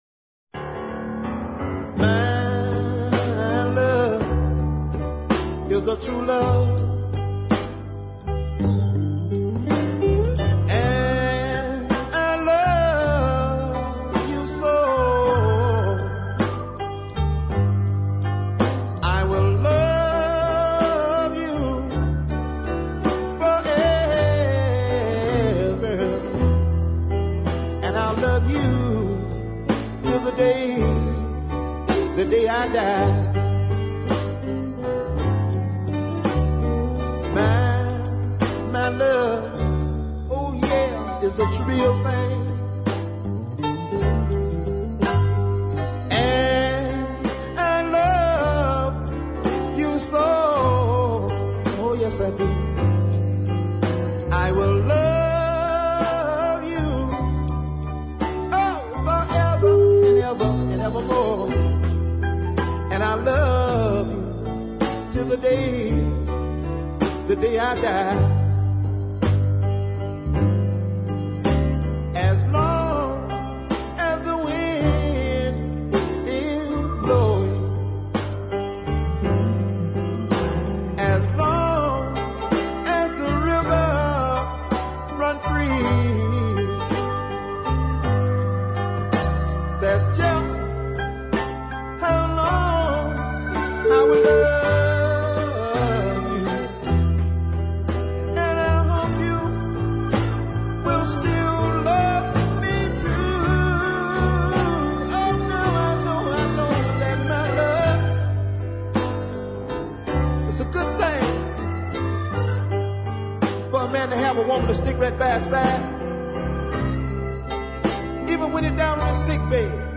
But the best of the bunch is the understated ballad